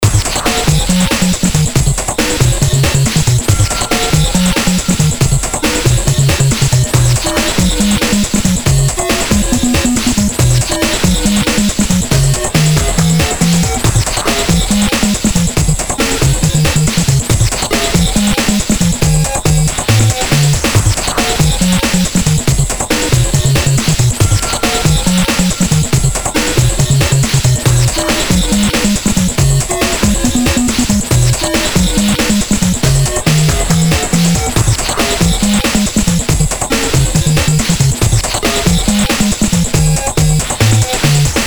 Just A Music File